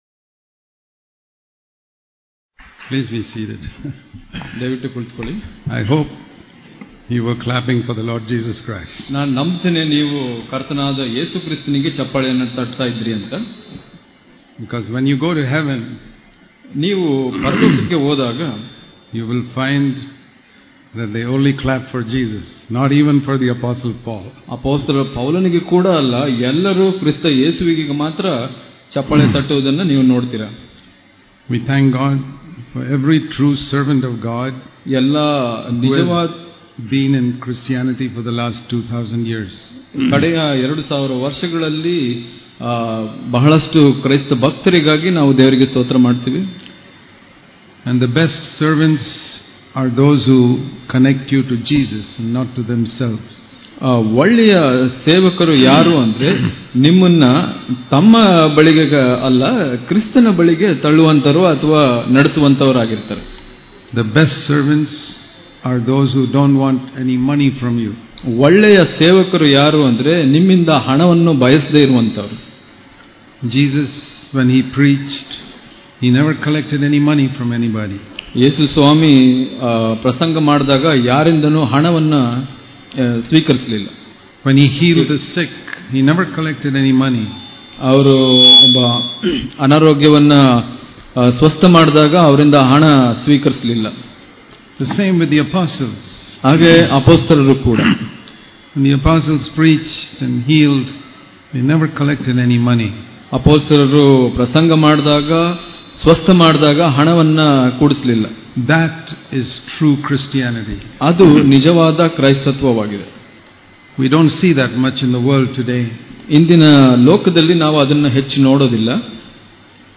ಧರ್ಮೋಪದೇಶದ